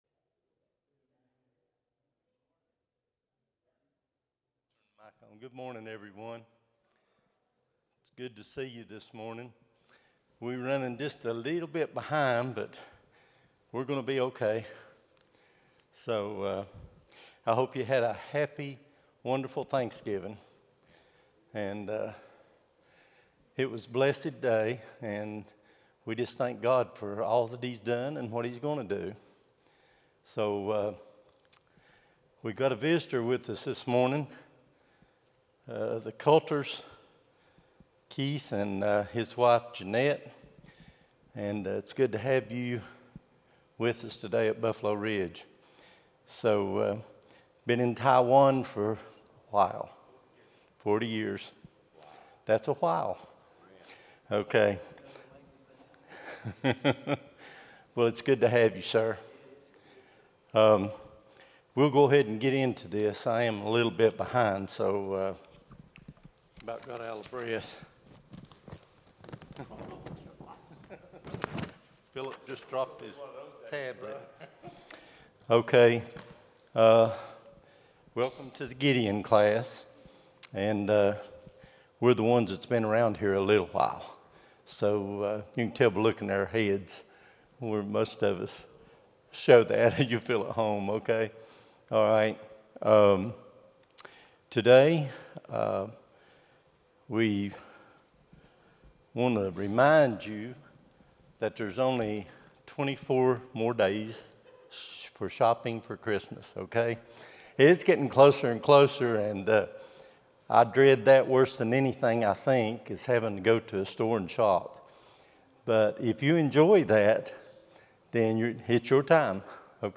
11-30-25 Sunday School | Buffalo Ridge Baptist Church